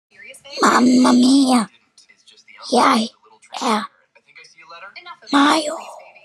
Hehehe Sound Button - Free Download & Play